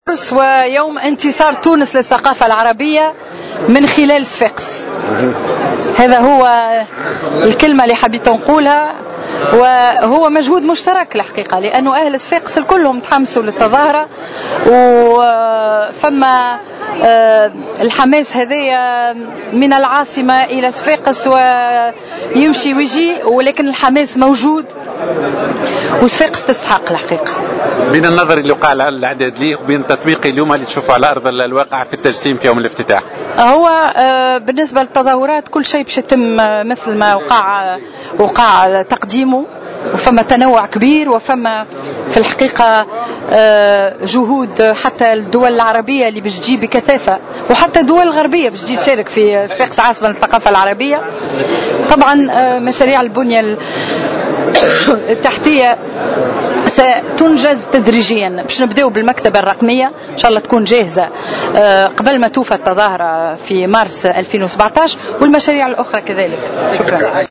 تم مساء اليوم السبت الاعلان رسميا عن صفاقس عاصمة الثقافة العربية 2016 خلال حفل افتتاحي مميز لهذه التظاهرة العربية في ساحة باب بحر بصفاقس والتي حضرتها شخصيات تونسية وعربية وأجنبية.